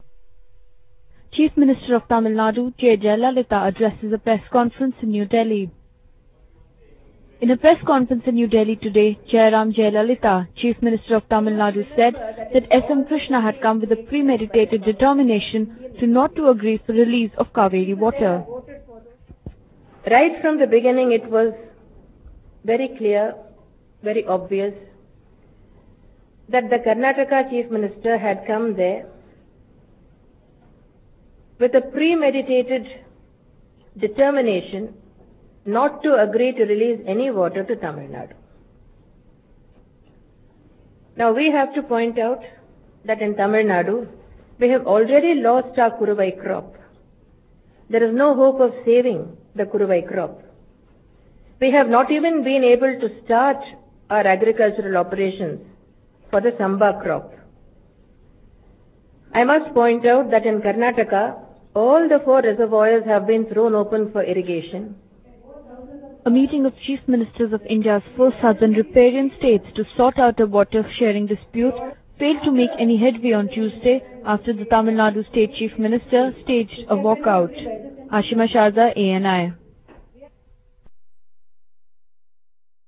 Tamil Nadu Chief Minister Jayalalitha during a Press conference in New Delhi on Wednesday. — PTI